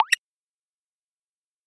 На этой странице собраны звуки кнопок меню — короткие и четкие аудиофрагменты, подходящие для приложений, сайтов и игр.
Шум мягкого нажатия